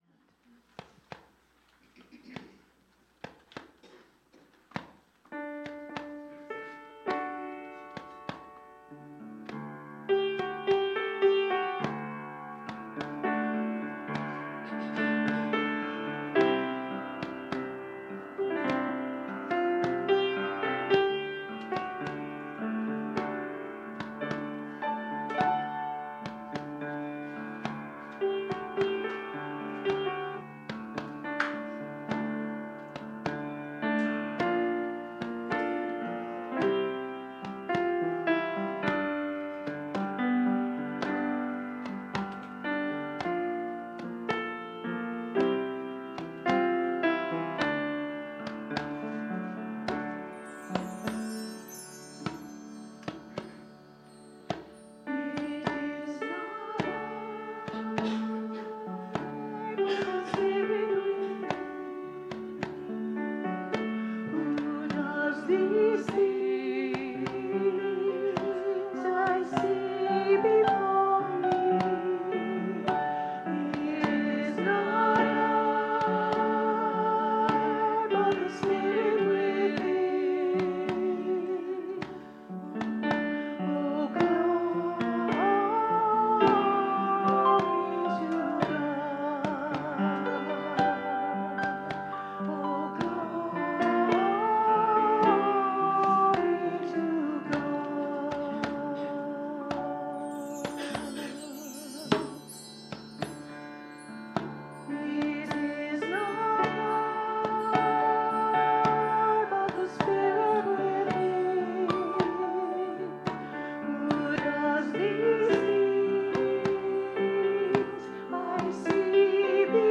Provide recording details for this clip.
The audio recording (below the video clip) is an abbreviation of the service. It includes the Meditation, Message, and Featured Song.